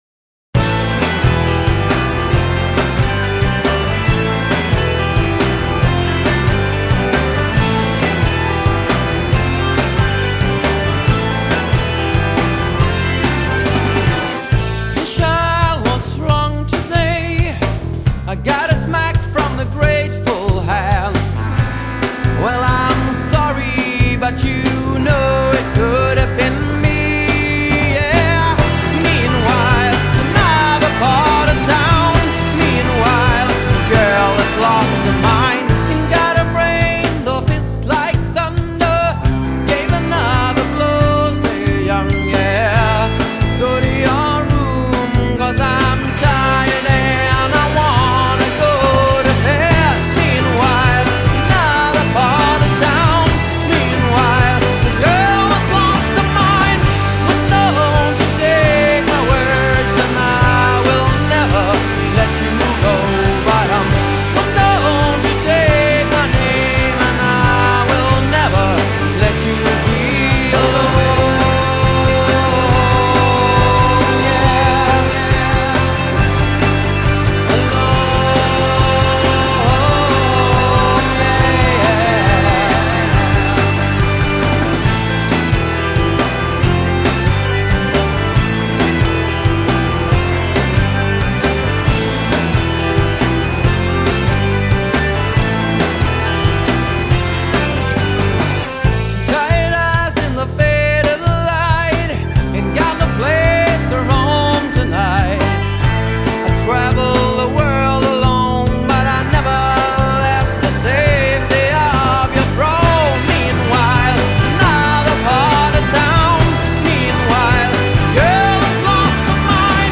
Accordion
Bass
Mandolin
Drums